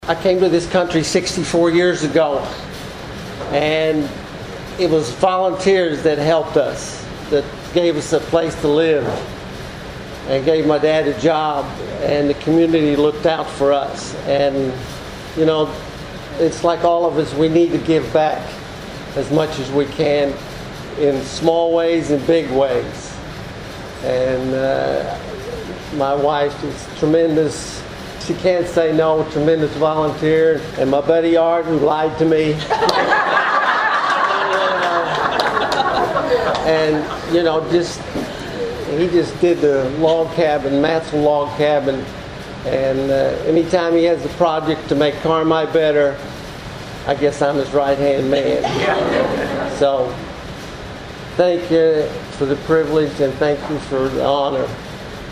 The city’s leaders and most influential business trailblazers gathered Monday night in a return to somewhat normal for their 77th annual Chamber of Commerce Annual Dinner.